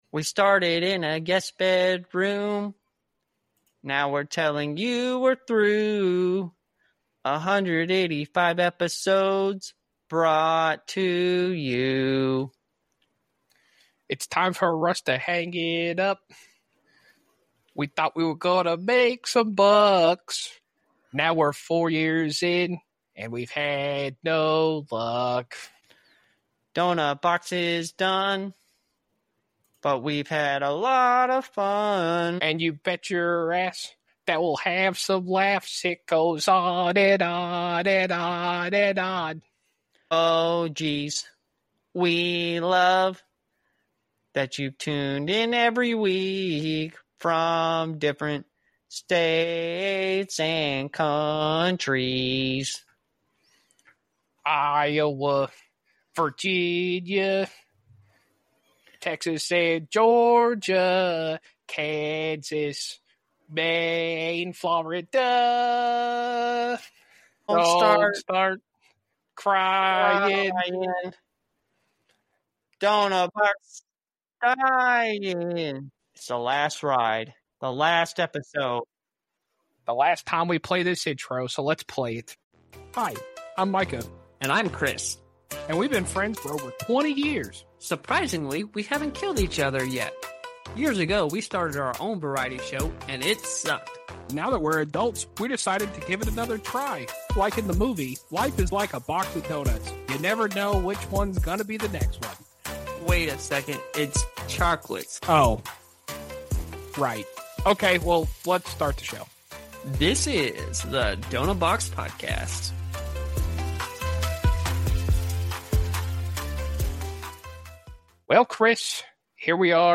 Variety show released every Monday morning. A bit of comedy and a bit serious, we love having lots of laughs and delve into some interesting topics.